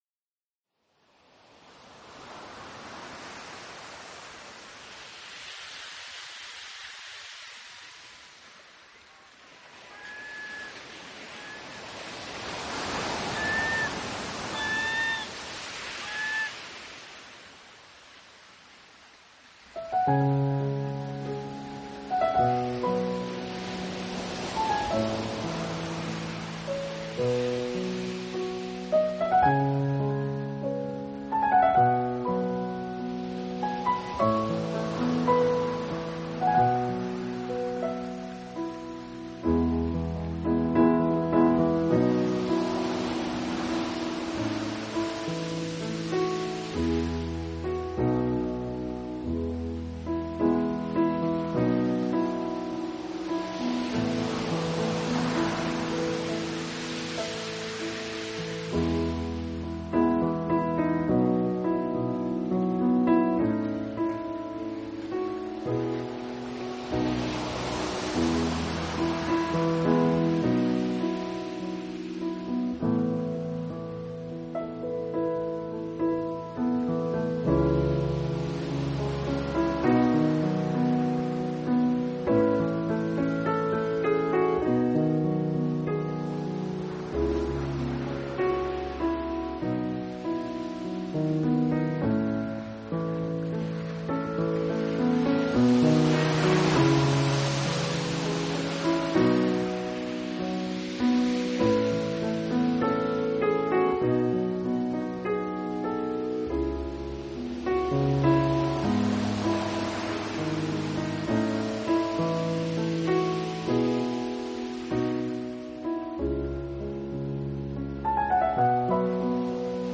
音乐类型：New Age
专辑语言：纯音乐 Environmental